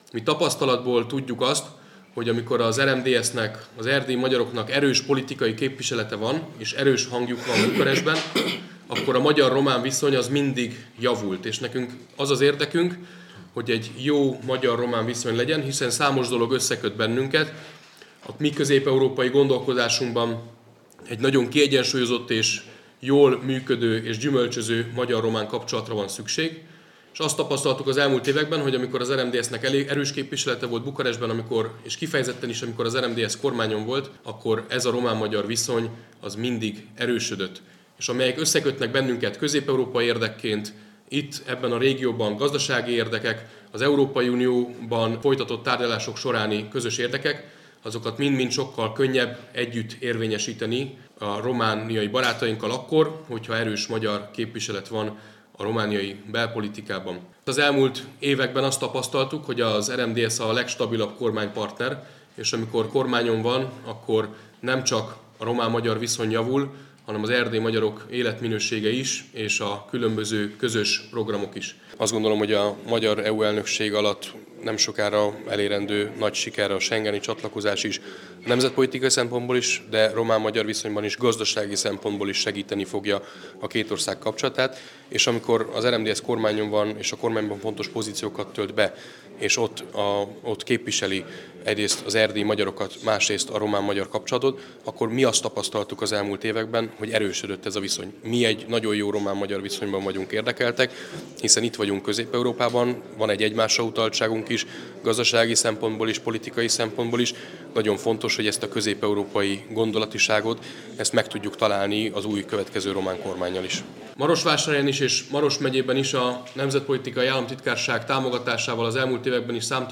A magyar-román államközi kapcsolatok mindig jobbak voltak, amikor az RMDSZ kormányon volt – mondta el marosvásárhelyi sajtótájékoztatóján Nacsa Lőrinc, a magyar kormány nemzetpolitikáért felelős államtitkára.